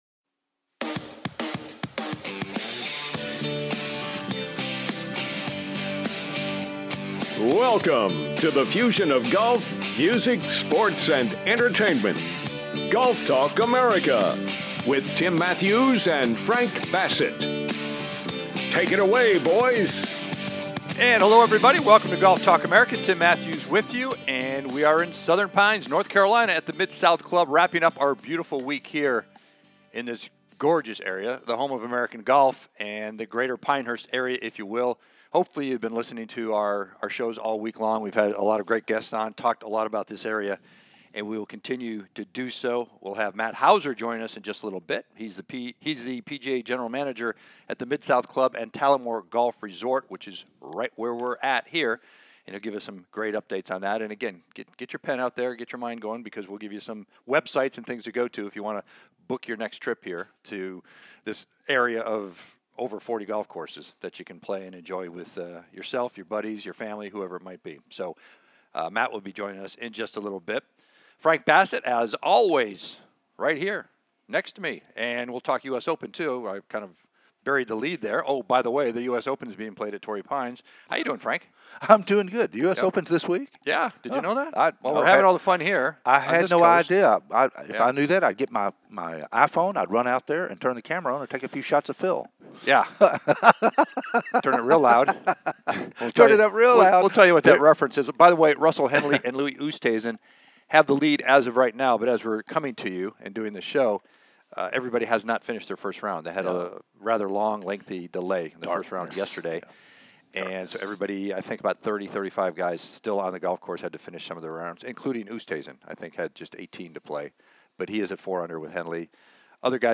"LIVE" FROM THE CROWS NEST AT THE MID SOUTH CLUB IN SOUTHERN PINES, NORTH CAROLINA